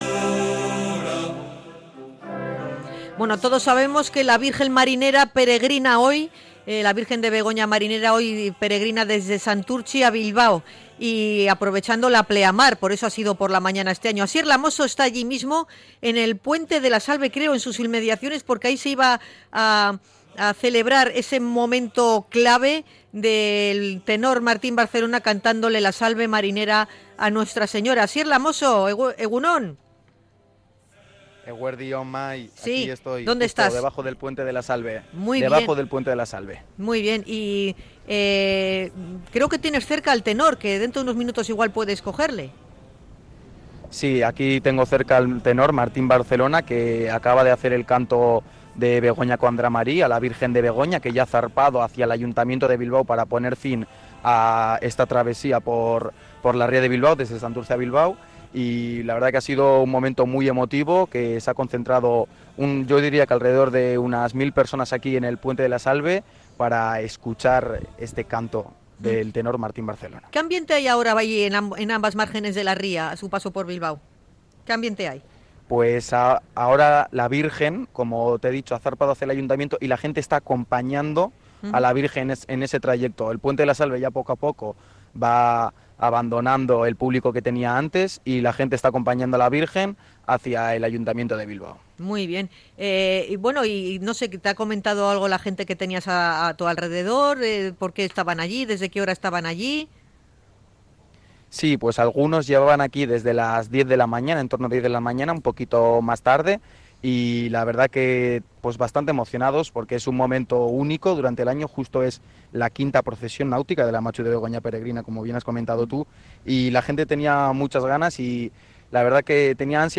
Desde Radio Popular – Herri Irratia hemos realizado una cobertura especial.
Y, más tarde, bajo el Puente de La Salve, donde hemos vivido el canto de la Salve.